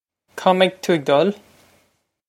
Pronunciation for how to say
Kaw meg too egg duhl?
This is an approximate phonetic pronunciation of the phrase.